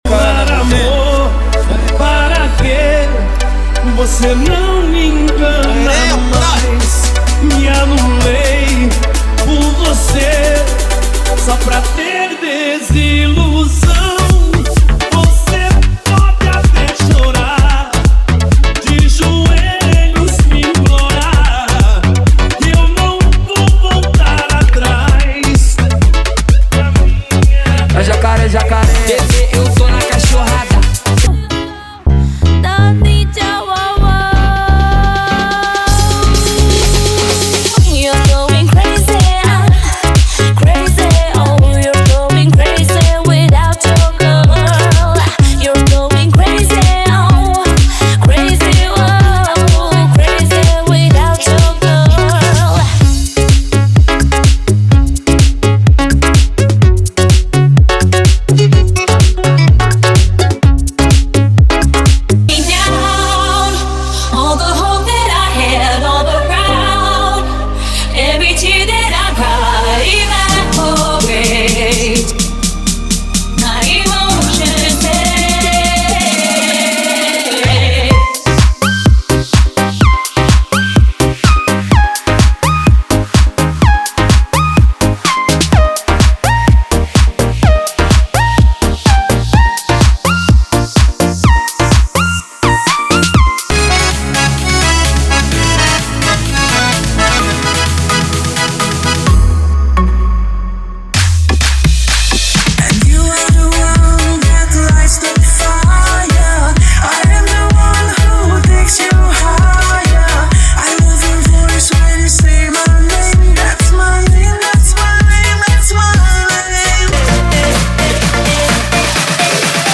Os Melhores Dance Comercial do momento estão aqui!!!
• Sem Vinhetas
• Em Alta Qualidade